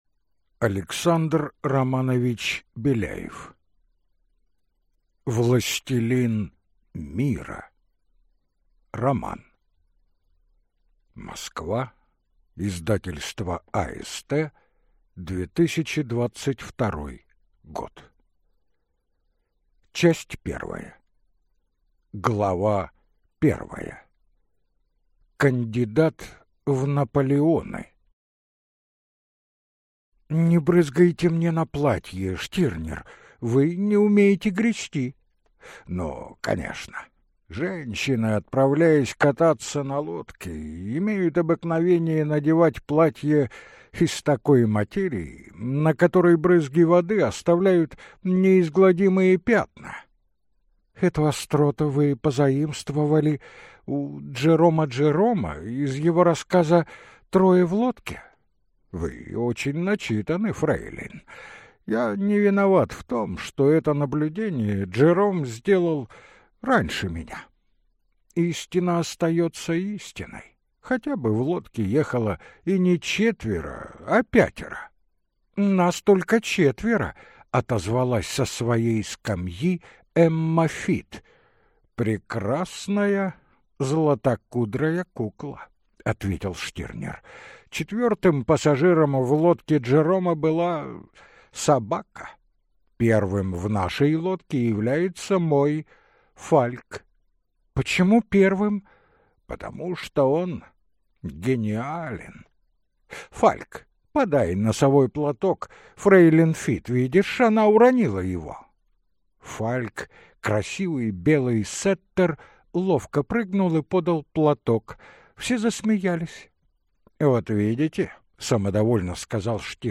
Аудиокнига Властелин Мира | Библиотека аудиокниг